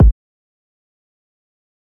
TM SIZZ KICK1.wav.wav